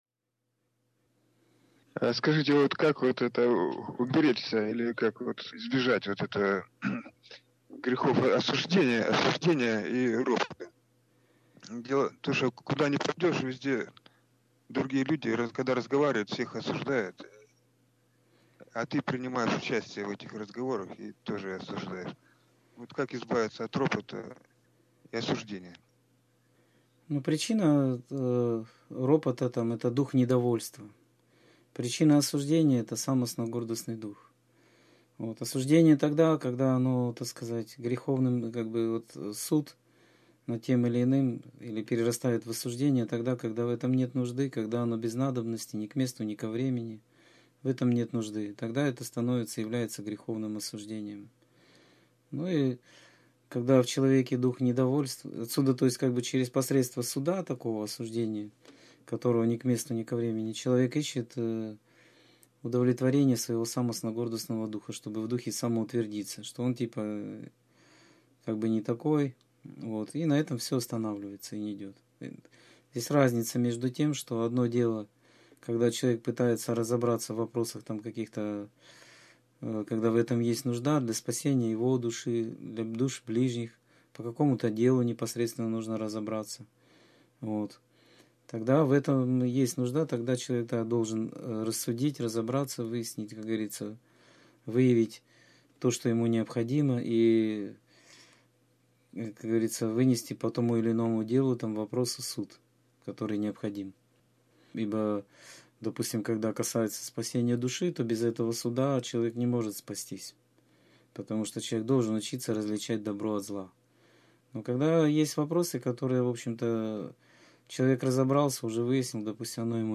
Скайп-беседа 26.10.2013